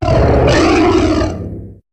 Cri de Démétéros dans sa forme Totémique dans Pokémon HOME.
Cri_0645_Totémique_HOME.ogg